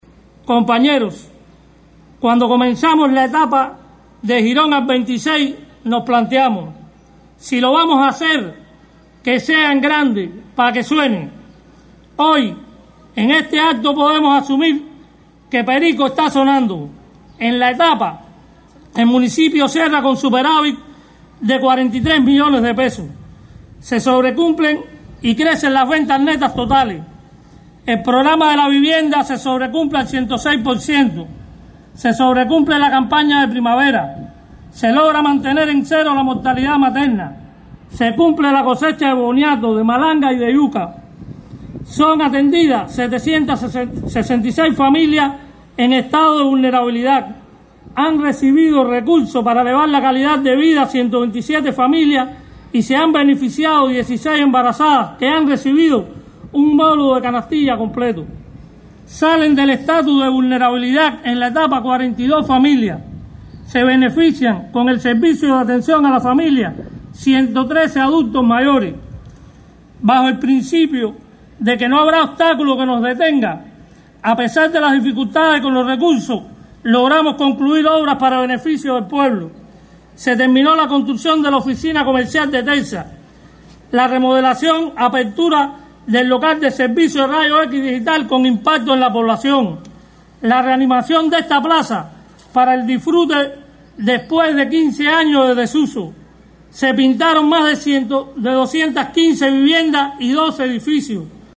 Méritos suficientes para que este territorio fuera la sede hoy del acto provincial por el Día de la Rebeldía Nacional.
Carlos Clemente Carmona, primer secretario del Partido Comunista de Cuba (PCC) en este municipio, resaltó lo que se ha hecho en el territorio superavitario, con aportes relevantes en la producción de alimentos, un trabajo destacado en la Cultura, el Deporte, la Salud, la Educación y en el vínculo directo y sistemático de las estructuras del Partido y el Gobierno con las comunidades.